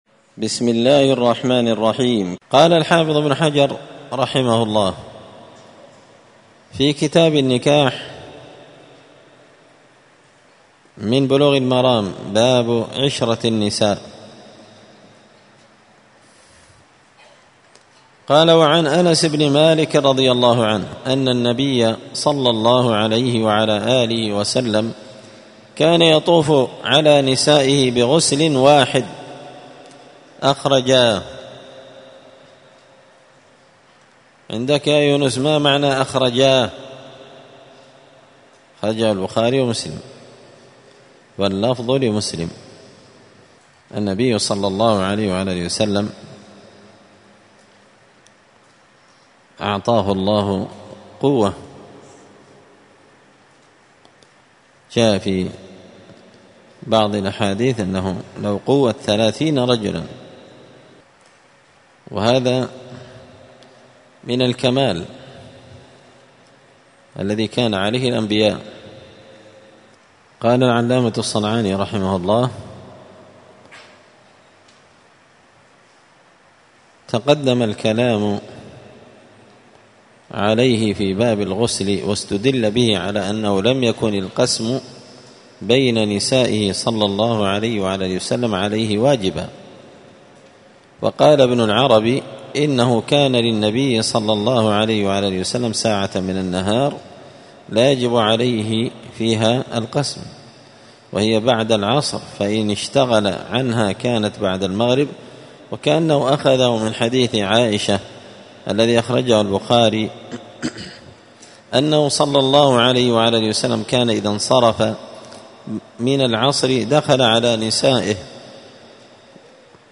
*الدرس 24 {تكملة باب عشرة النساء وبداية باب الصداق}*